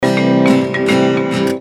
• Качество: 320, Stereo
гитара
без слов
Перебор южного струнного инструмента